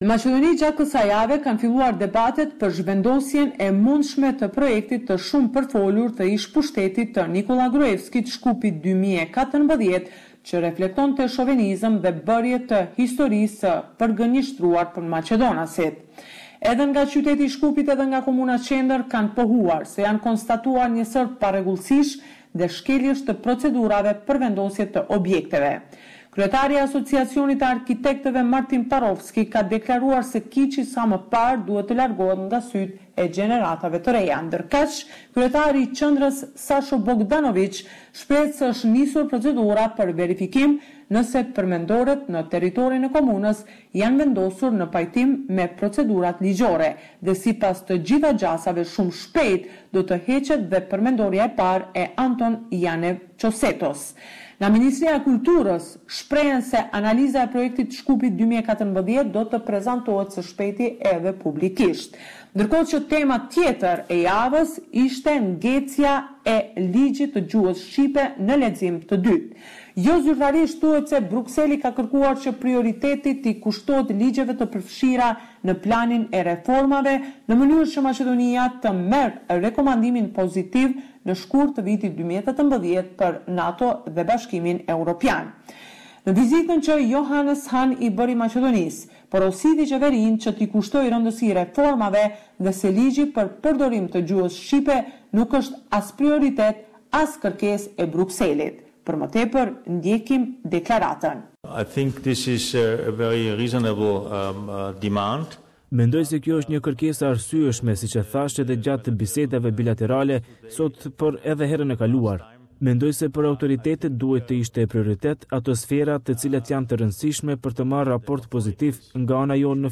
This is a report summarising the latest developments in news and current affairs in FYROM.